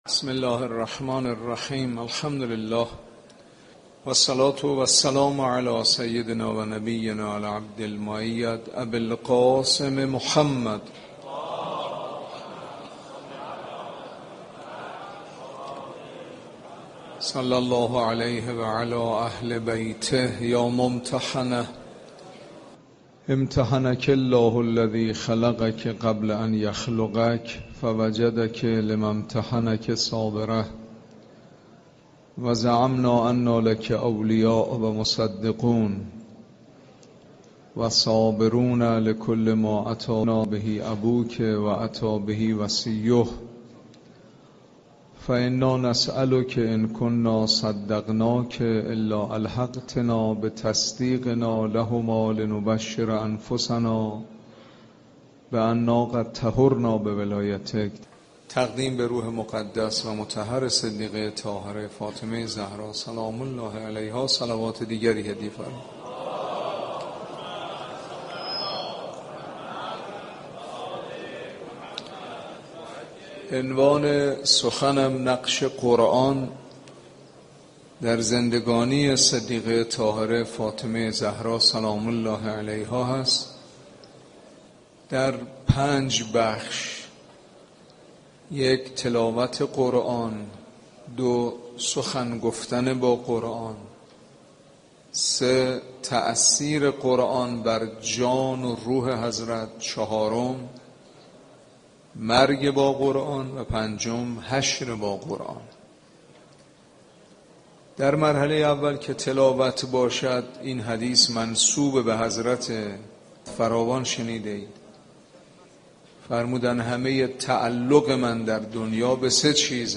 صوت سخنرانی مذهبی و اخلاقی موضوع بحث نقش قرآن در زندگی حضرت فاطمه زهرا (س) است که در پنج بخش وارد شده است: اول تلاوت قرآن؛ دوم سخن گفتن با قرآن؛ سوم تأثیر قرآن بر جان و روح حضرت؛ چهارم مرگ با قرآن و پنجم حشر با قرآن.